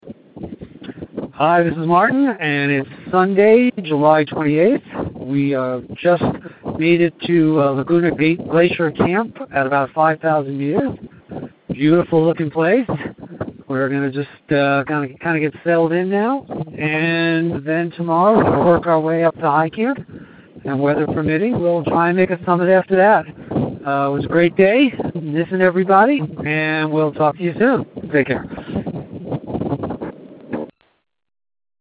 Bolivia Expedition Dispatch